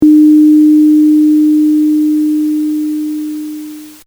Here’s the same signal dithered, truncated to seven bits:
7-bit sine tone, dithered
In both cases, the dithered signal maintains the sine tone throughout, at the expensive of added “hiss”.
7bit_dithered.mp3